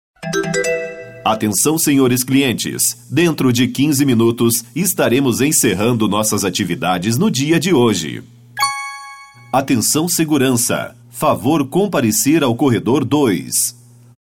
Com nosso sistema você terá mensagens pré-gravadas com a mesma qualidade e entonação de um locutor profissional, nada robotizado.
locutor-virtual-03.mp3